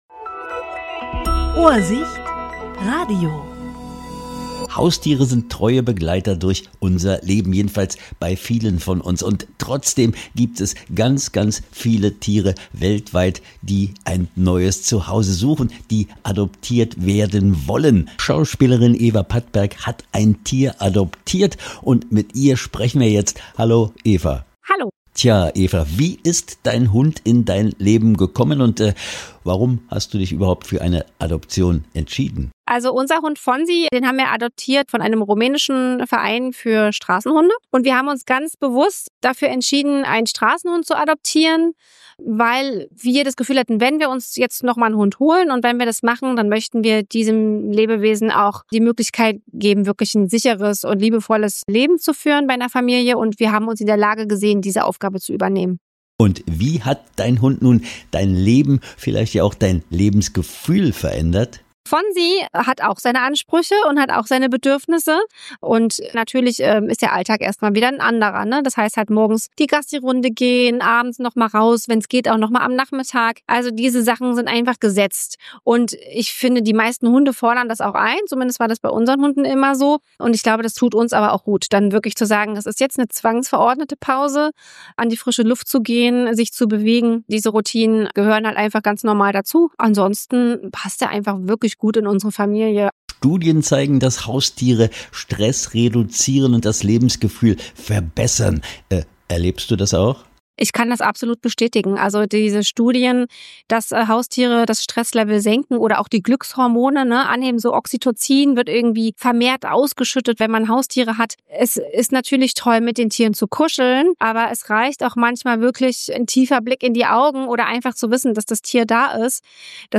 Interview 17.10.2025